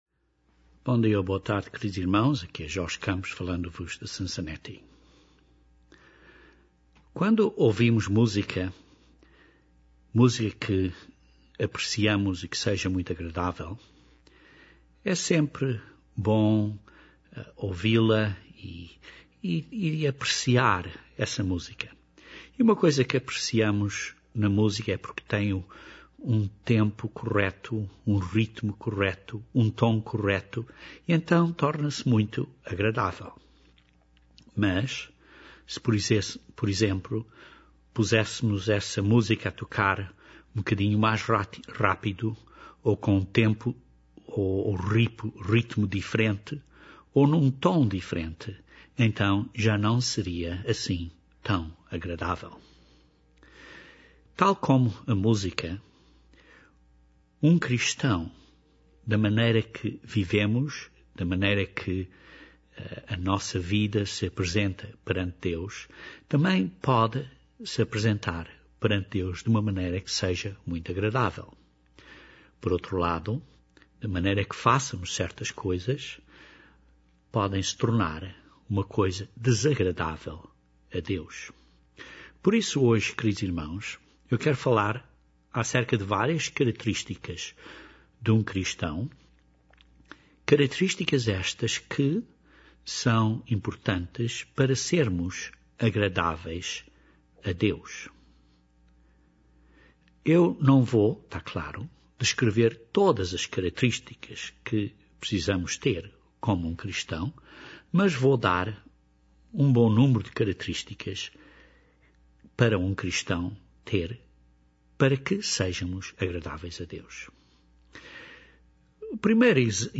Igualmente as nossas características Cristãs precisam de estar em 'tom' para serem agradáveis a Deus. Este sermão descreve várias características Cristãs, para analisarem pessoalmente, e para verem se estão a agradar a Deus na vossa vida.